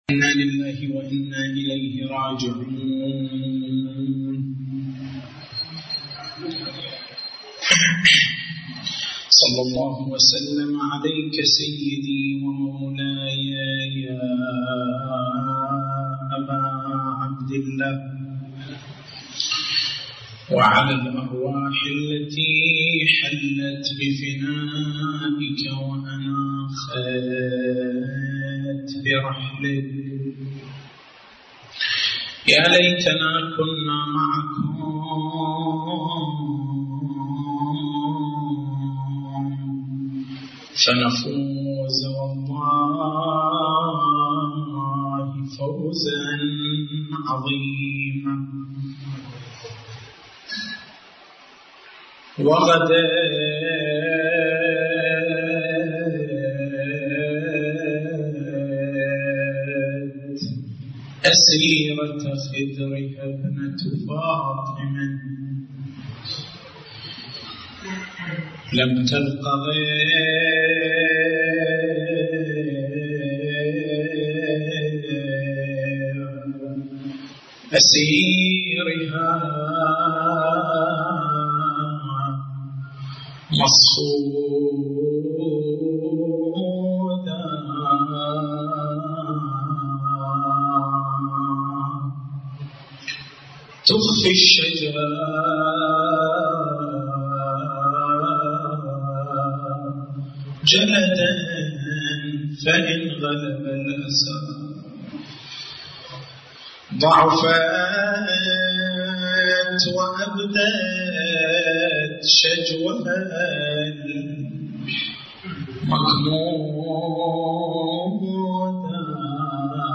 تاريخ المحاضرة: 24/09/1431 نقاط البحث: بيان رؤية الفقهاء للشهادة الثالثة رأي مشهور الفقهاء رأي بعض المتقدّمين رأي السيد محسن الحكيم ما هو الدليل على استحباب الشهادة الثالثة؟